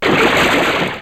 Splash
Splash.wav